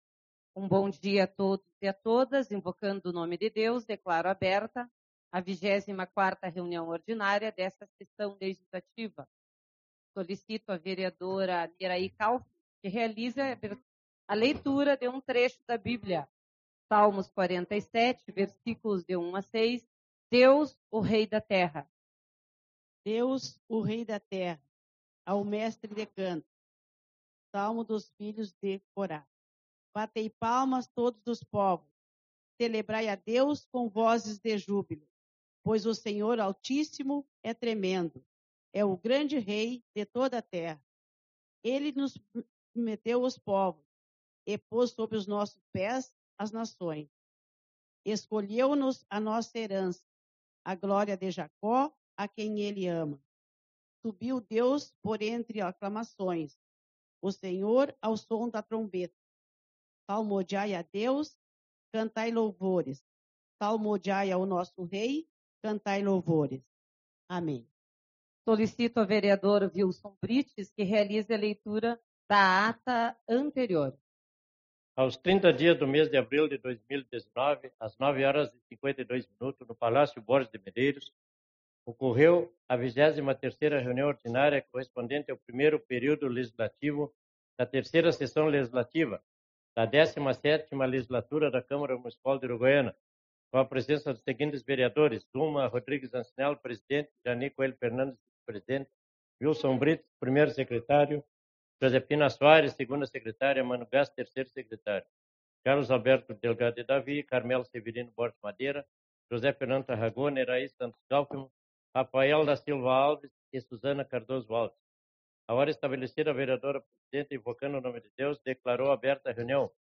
02/05 - Reunião Ordinária